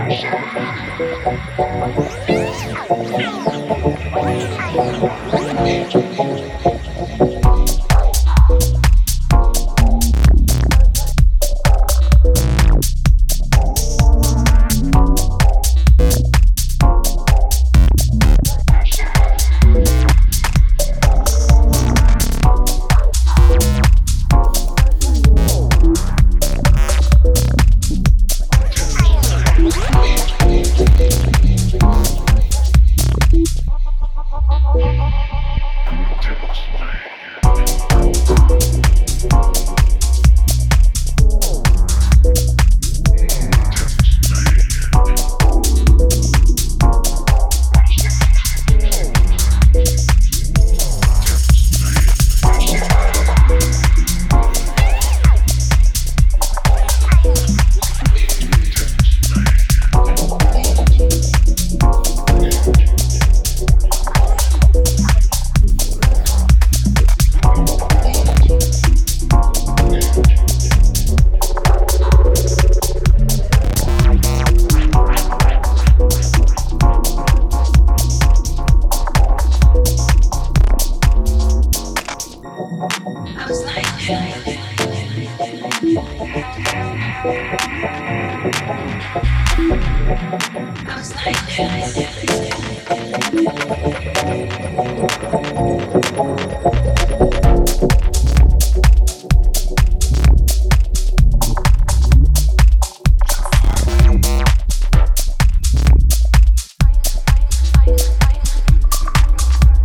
Recorded in Berlin
funky guitar bassline and smooth, uplifting groove